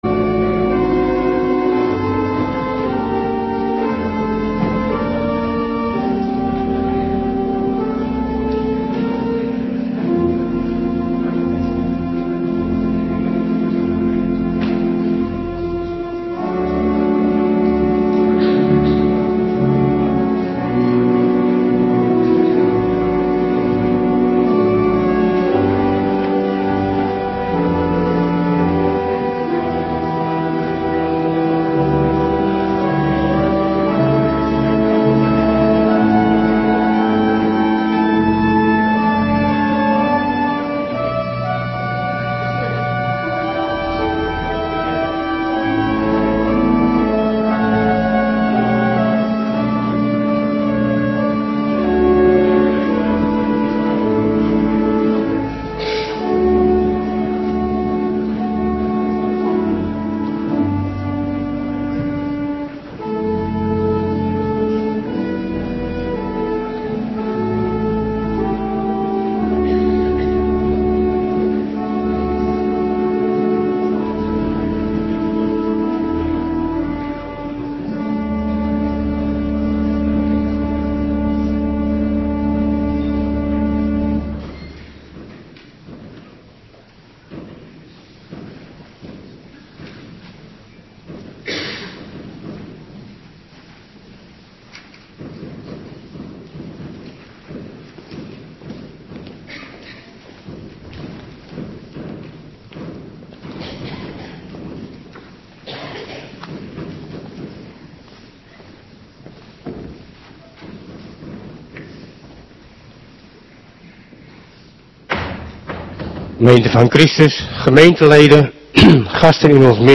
Avonddienst 31 december 2025